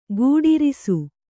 ♪ gūḍirisu